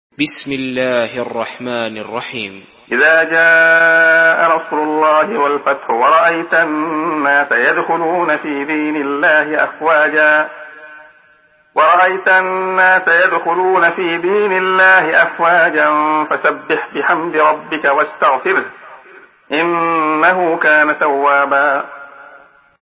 Surah An-Nasr MP3 in the Voice of Abdullah Khayyat in Hafs Narration
Murattal